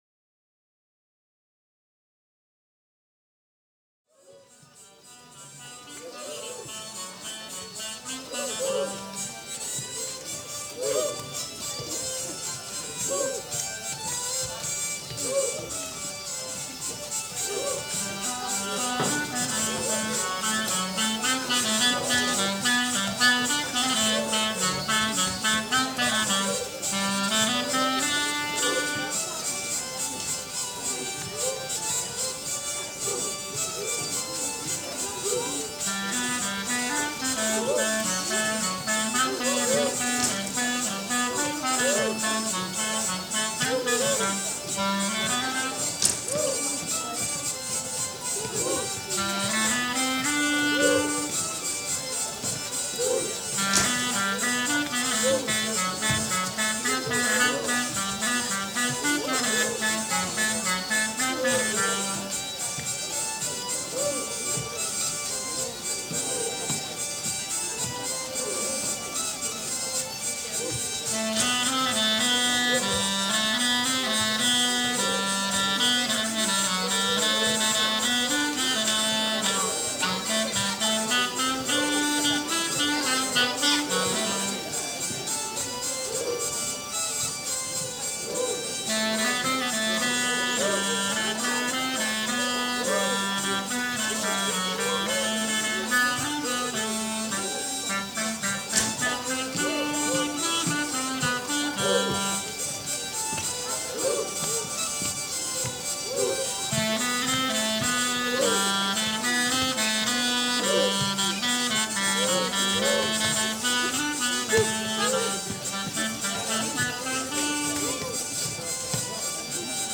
:mp3:2017:07_festival:mercredi
01_intro_enfants.mp3